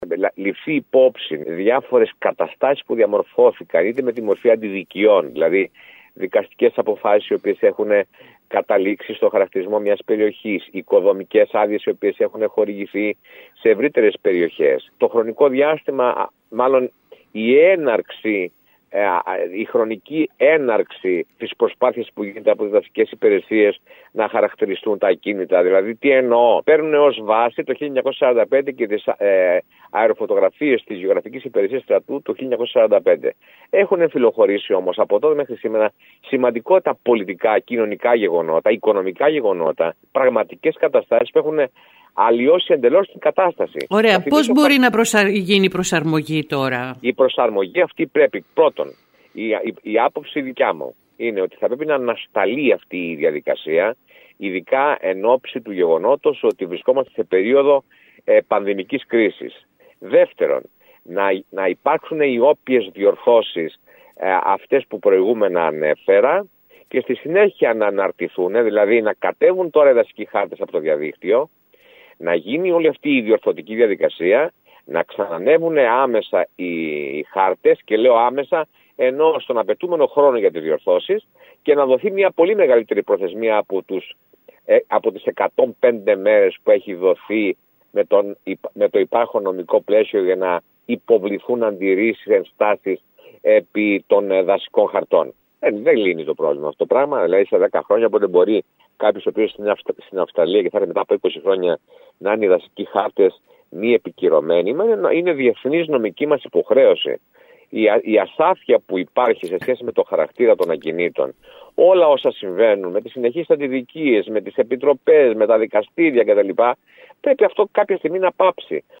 Μιλώντας σήμερα στην ΕΡΑ ΚΕΡΚΥΡΑΣ ανέφερε ότι η πρόταση του ΤΕΕ για δυνατότητα ενστάσεων στο διηνεκές, χωρίς προθεσμίες, δεν είναι δυνατόν να εφαρμοστεί λόγω της υποχρέωσης της χώρας μας να καταρτίσει σύντομα δασικούς χάρτες και μάλιστα επικυρωμένους μετά από τελεσίδικες διαδικασίες.